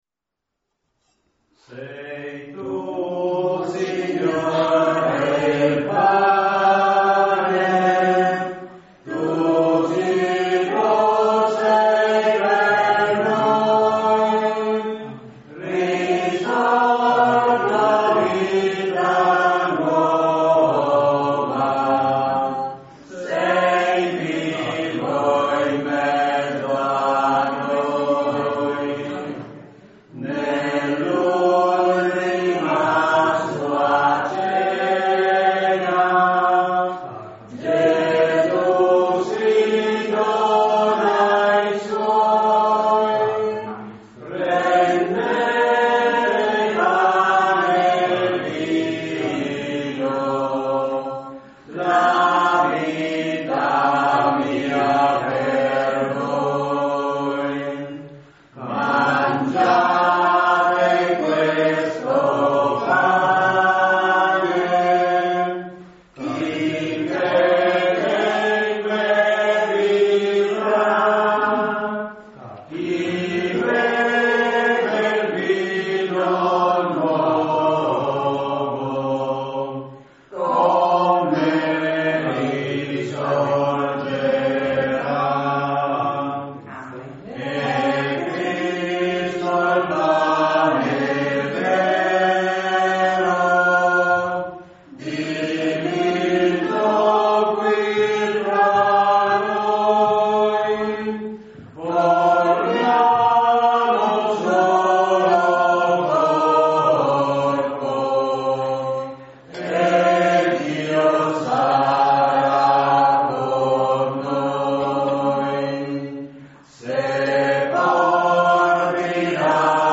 Santa Messa nella chiesetta di San Lorenzo
CANTO ALLA COMUNIONE